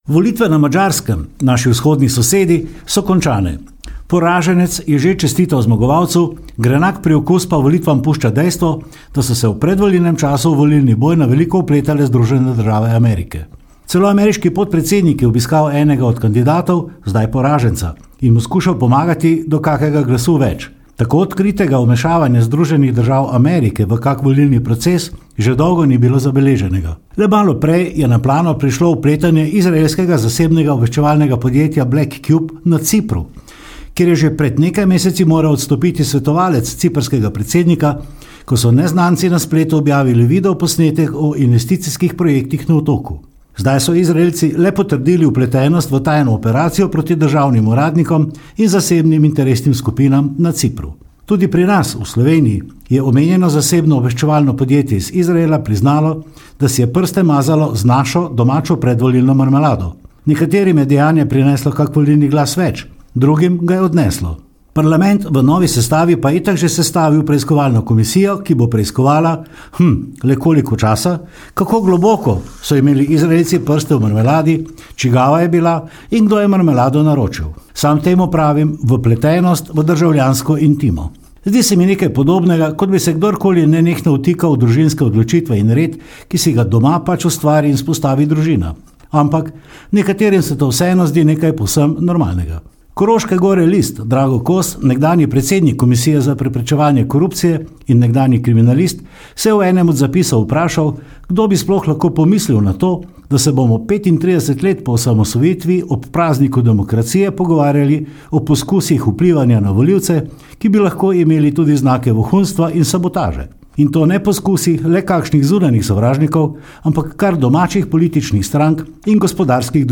komentar_4.mp3